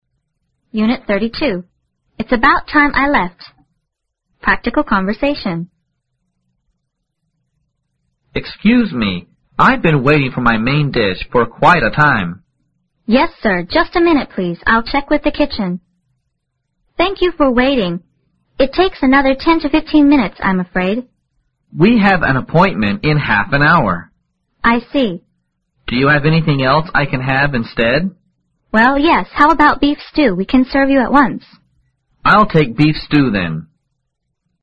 W----woman M----man